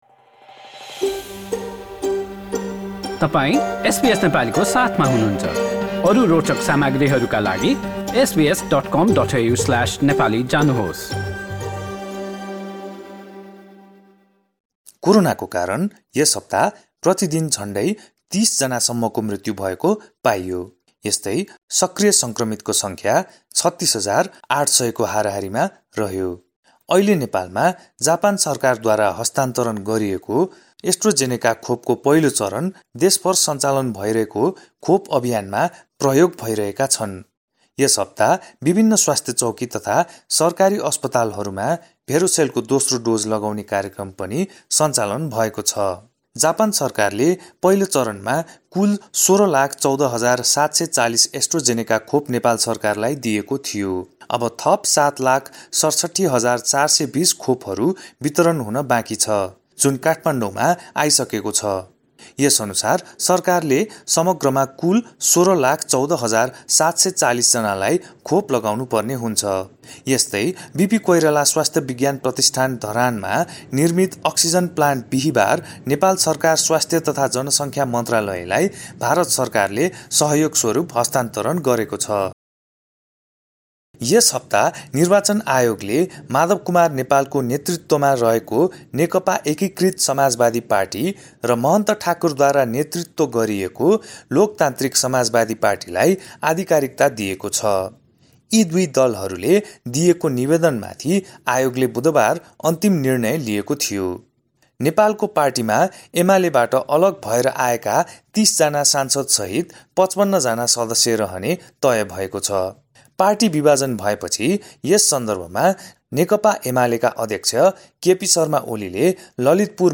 A text version of this news report is available in the Nepali language version of our website.